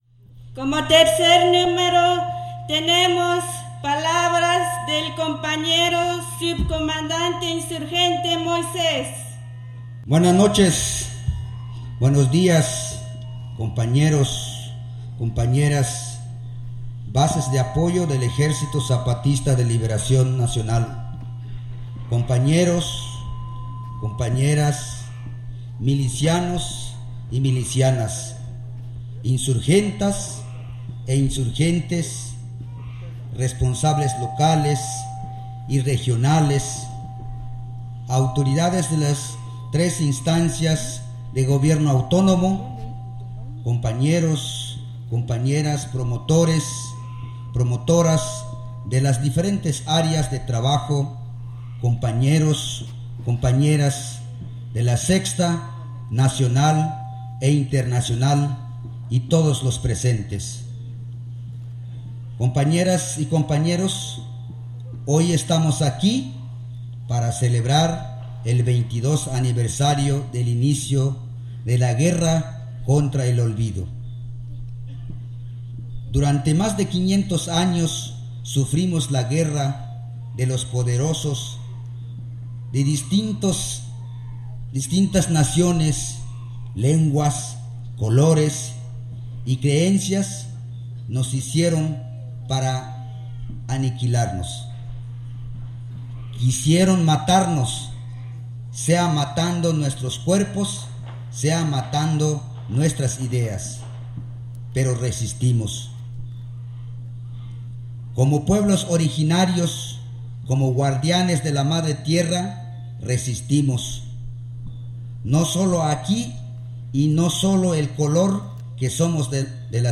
Descarga el audio: Palabras del Subcomandante Insurgente Moisés en Oventic